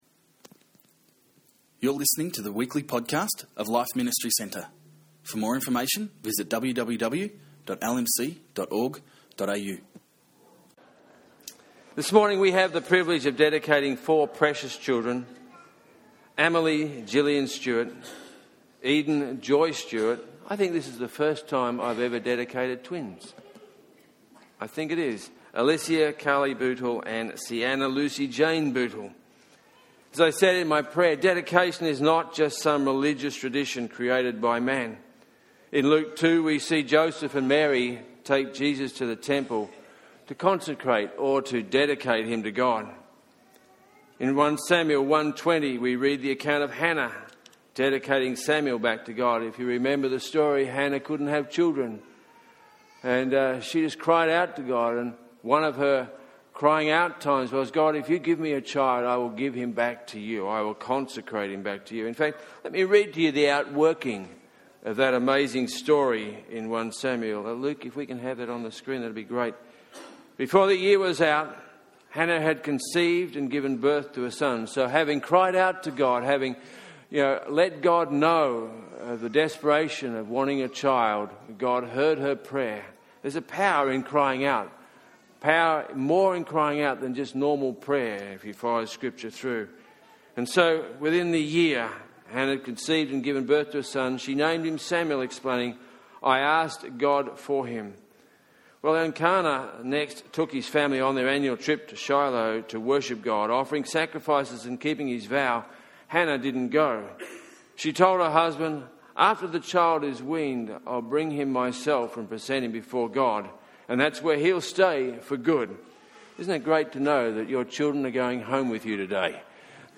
Baby Dedication Service